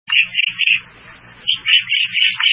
En cliquant ici vous entendrez le chant du Pic noir.
Le Pic noir